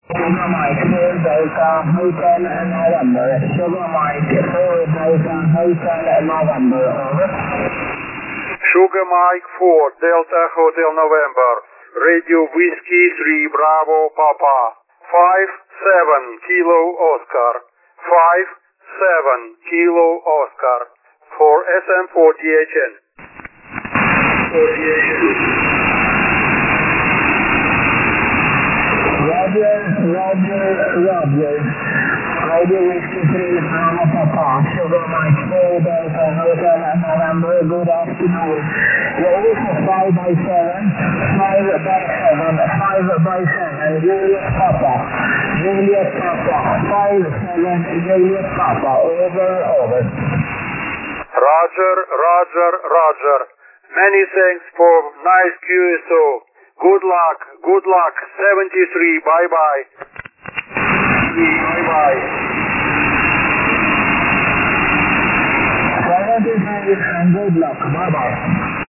Ниже приведены фрагменты записи моих связей в формате mp3. Длительность своей передачи урезал с помощью редактора.